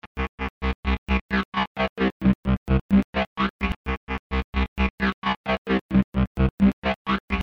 描述：来自我的曲目"do it"一个较少切分的低音循环，发挥出古怪的声音。
Tag: 130 bpm Electro Loops Bass Loops 1.24 MB wav Key : Unknown